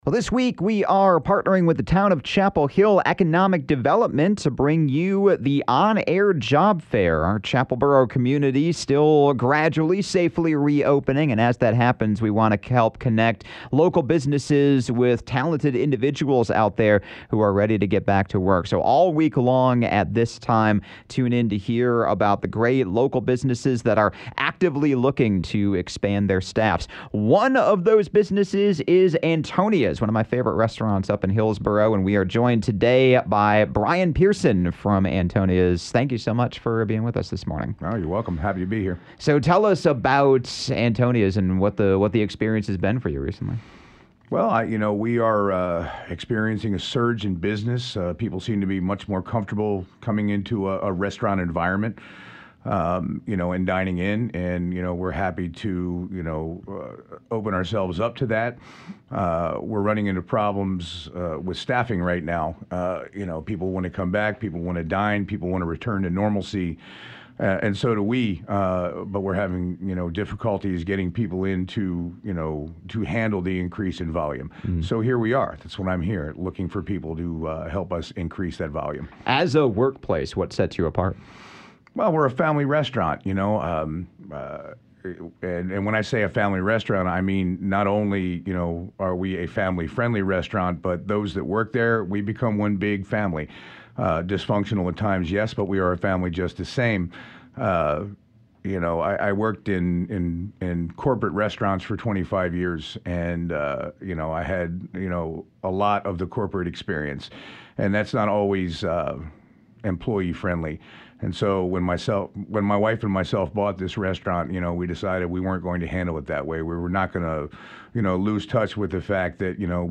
Tune in to the On-Air Job Fair at 7:30am and 5:30pm every day beginning Monday, May 17th through May 21st for exclusive interviews with local business owners looking to hire.